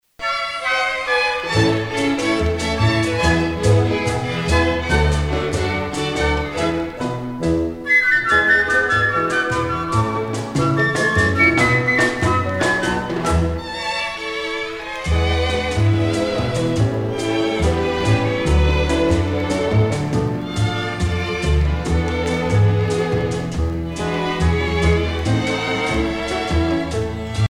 danse : danzón
Pièce musicale éditée